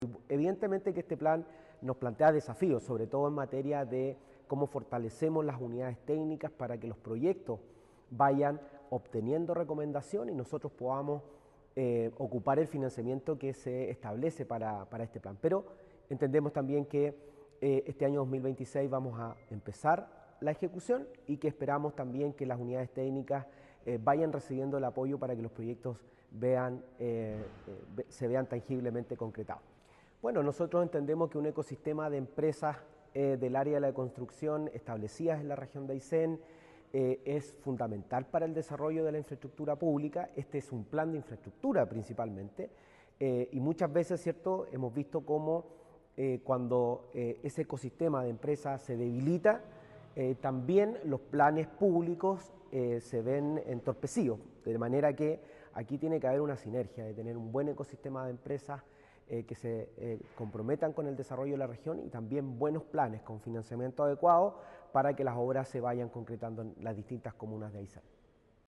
Gobernador Regional Marcelos Santana